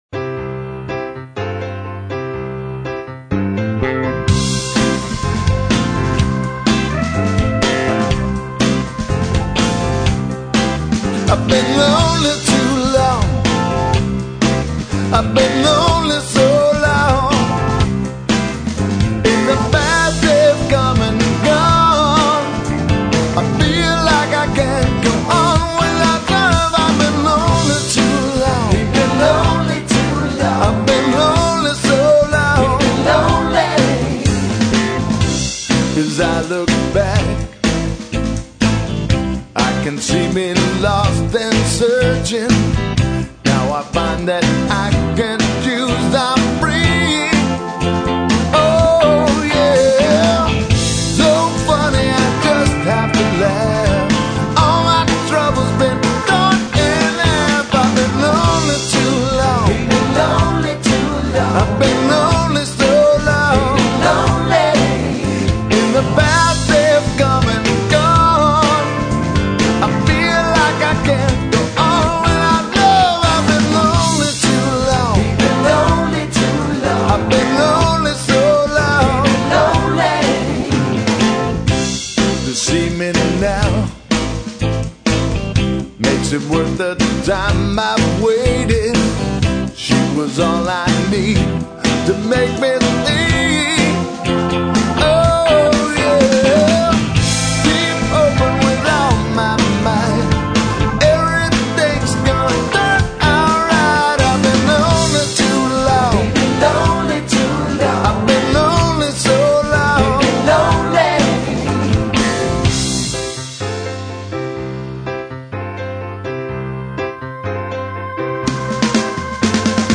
vocals, guitars, background vocals
piano
organ
bass
drums